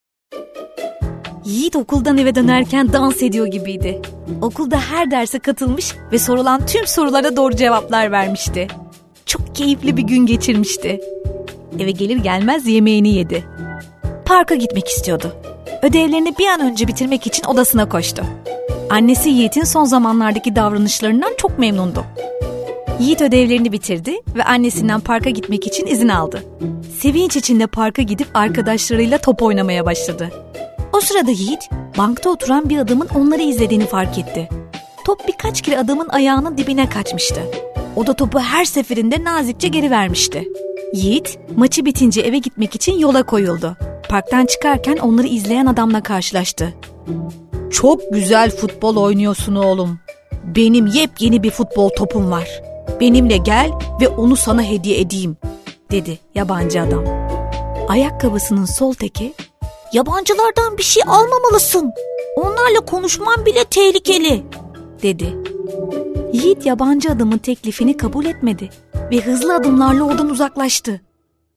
El servicio de locución de libros se realiza en nuestro estudio ubicado en Estambul, Şişli, Ortaklar Cad., donde servimos como agencia de locución y doblaje, acompañados por un director de sonido.